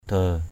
/d̪ə:/ (t.) bao nhiêu?; cỡ, bằng = combien? égal à, de la taille de; mesure, modèle. how much/many?; equal. de halei? d^ hl]? bằng cỡ bao nhiêu? parabha de gep...